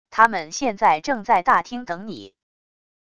他们现在正在大厅等你wav音频生成系统WAV Audio Player